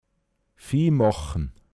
pinzgauer mundart
viimåchn Hochzeitsbrauch (Episoden aus dem Leben der Brautleute darstellen)